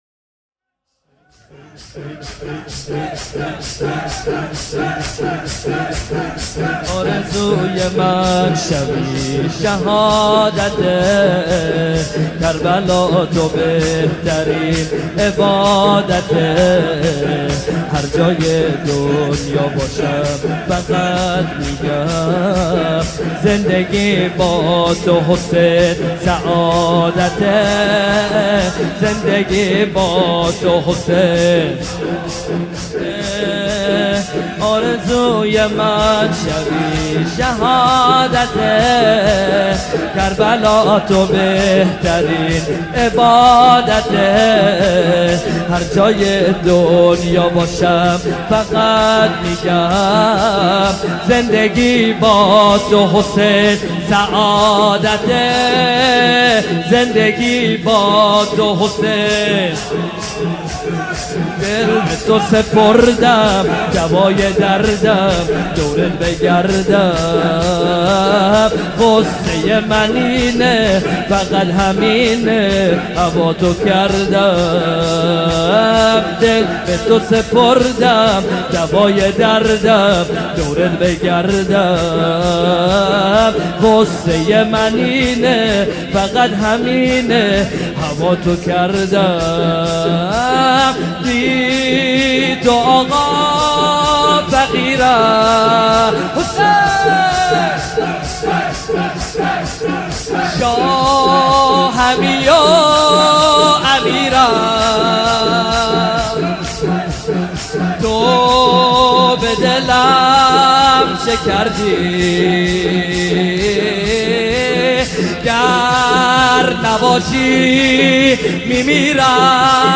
شور | آرزوی من شبی شهادته
مداحی
شب هفتم محرم 1439هجری قمری | هیأت علی اکبر بحرین